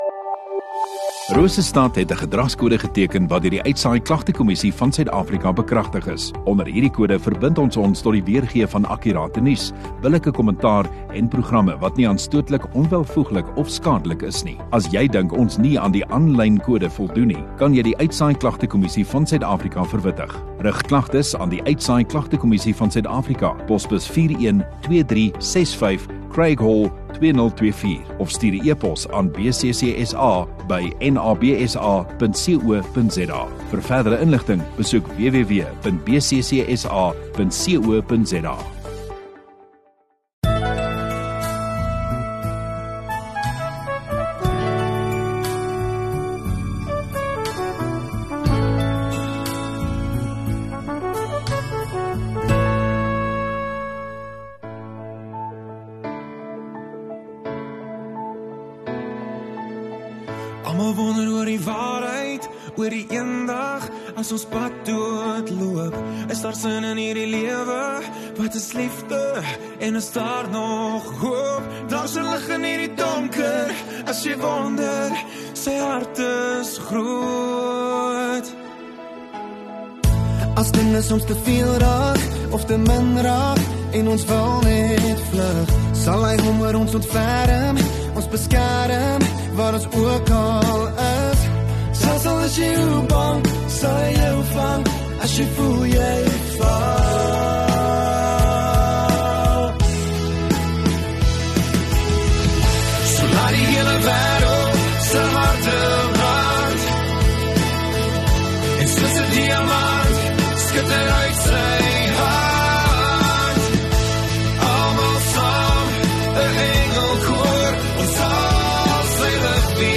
3 Apr Vrydag Oggenddiens